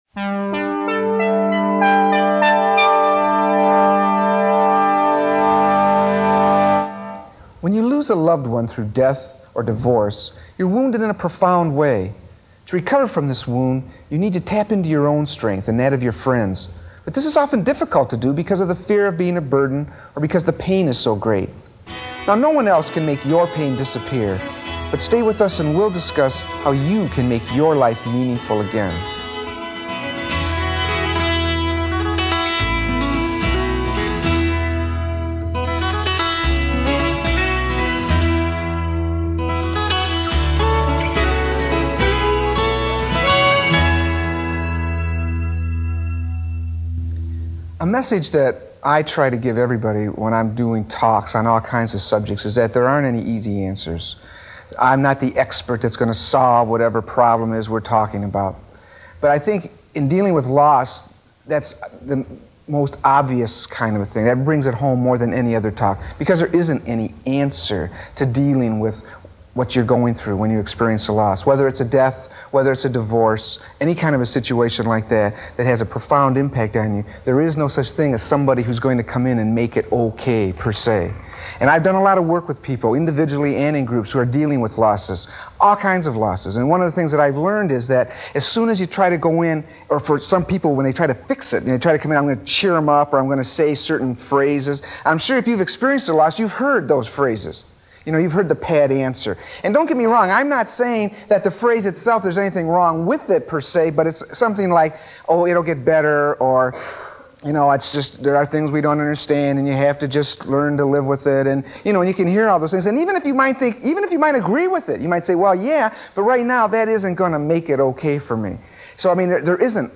An audio recording of one of the six part presentations I did for Public Television. This one is called Coping With Loss and comes out of my experiences of working with people who have lost loved ones. Some of these losses were due to suicide and murder as well as illnesses and accidents.